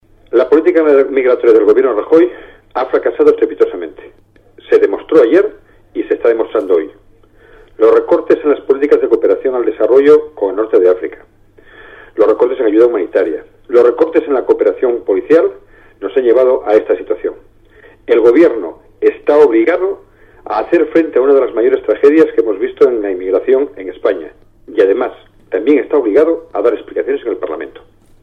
Declaraciones de Antonio Trevín tras la llegada masiva de inmigrantes 13/08/2014